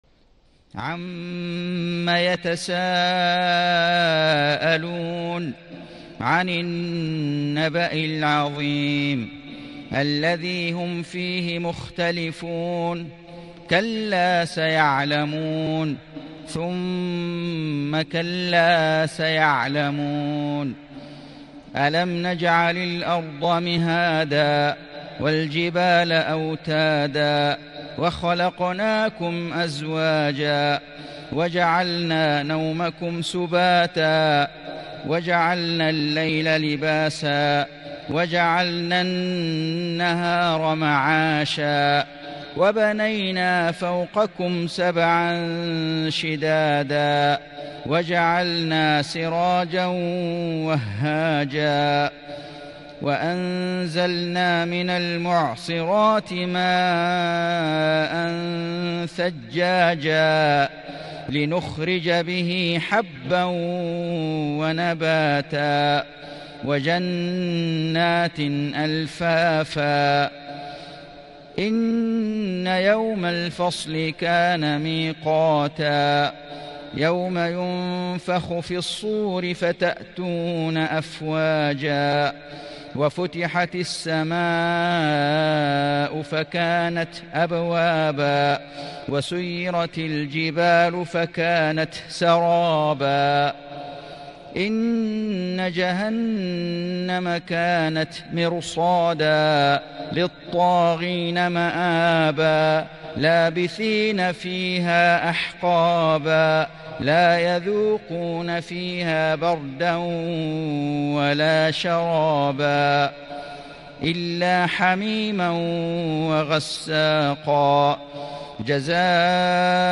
سورة النبأ > السور المكتملة للشيخ فيصل غزاوي من الحرم المكي 🕋 > السور المكتملة 🕋 > المزيد - تلاوات الحرمين